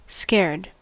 Ääntäminen
IPA: /ˈɛŋstlɪç/ IPA: [ˈʔɛŋstlɪç]